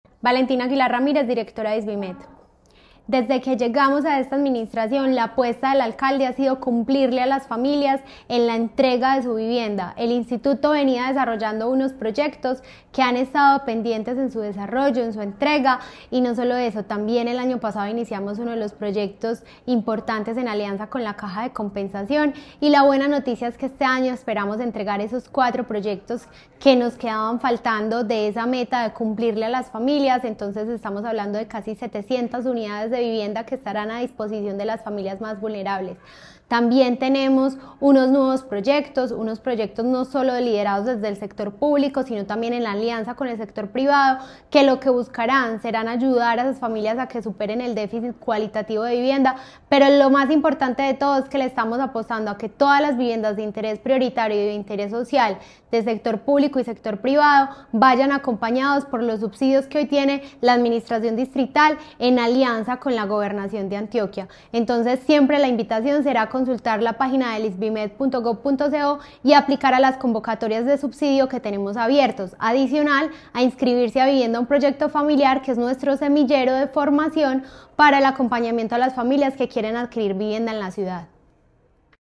Programas como Compra tu Casa y Vivienda, un Proyecto Familiar, facilitan el acceso a la vivienda para las familias más vulnerables. Declaraciones directora de Isvimed, Valentina Aguilar Ramírez Durante el primer semestre de 2025, la ciudad vendió 2.208 unidades de vivienda nuevas, un aumento de 37 % con respecto al mismo período del año anterior, según Camacol Antioquia .
Declaraciones-directora-de-Isvimed-Valentina-Aguilar-Ramirez.mp3